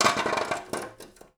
SPADE_Scrape_Asphalt_Vertical_mono.wav